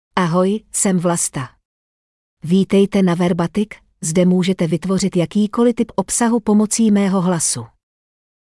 VlastaFemale Czech AI voice
Vlasta is a female AI voice for Czech (Czechia).
Voice sample
Listen to Vlasta's female Czech voice.
Female